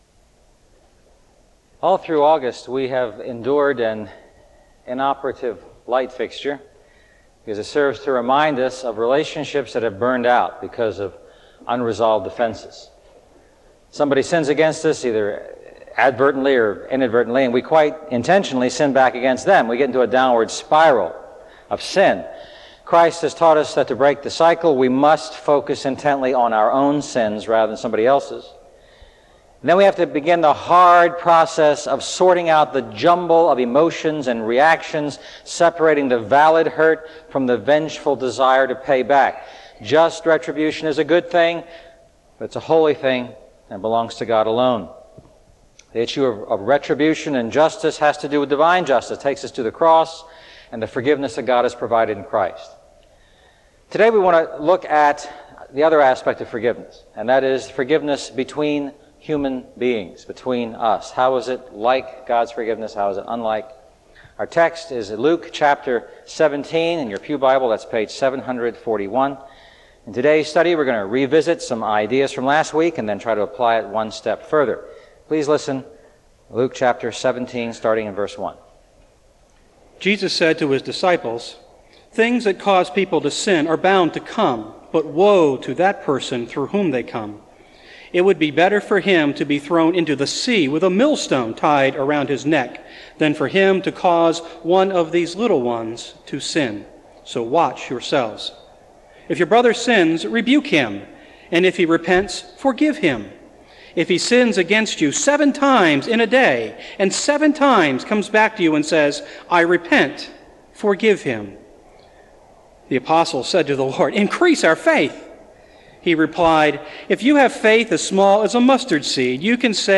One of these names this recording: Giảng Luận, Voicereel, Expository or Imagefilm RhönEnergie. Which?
Expository